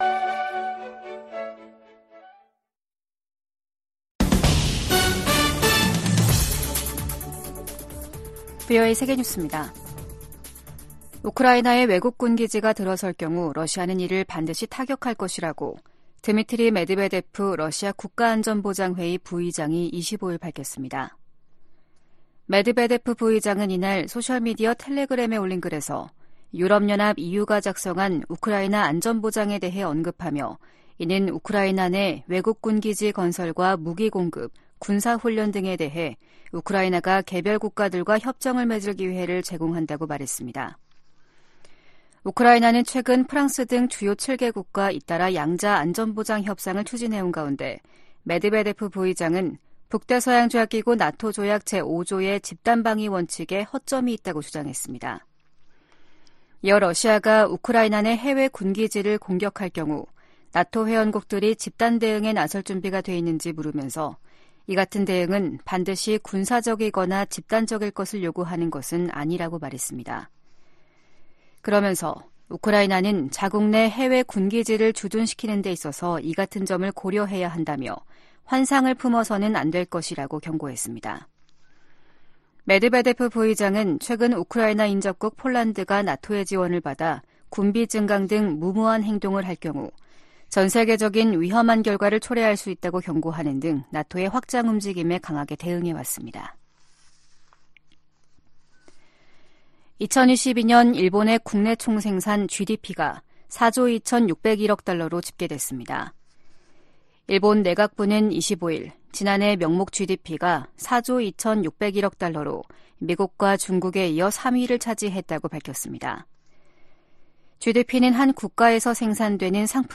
VOA 한국어 아침 뉴스 프로그램 '워싱턴 뉴스 광장' 2023년 12월 26일 방송입니다. 북한이 이번 주 노동당 전원회의를 개최할 것으로 보입니다.